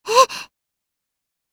neptune-confused.wav